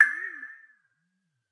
描述：A commodore 64 / submarine tone
Tag: 电子 小鼓